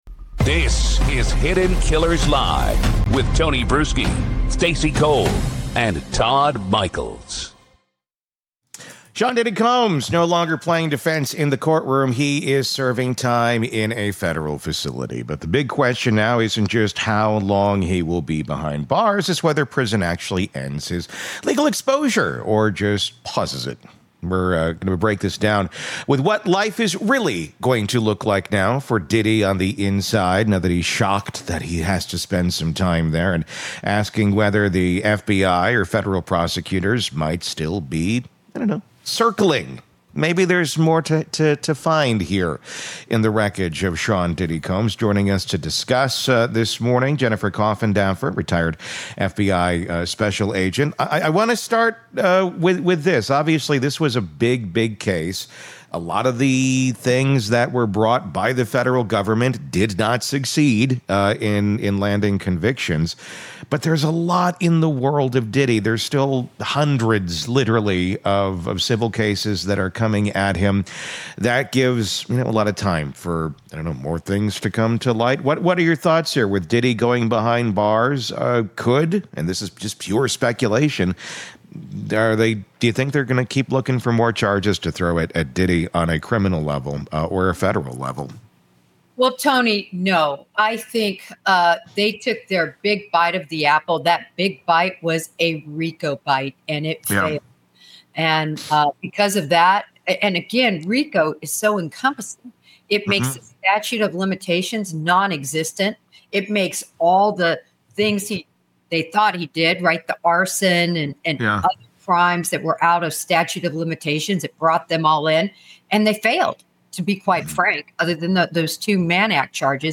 FBIInterview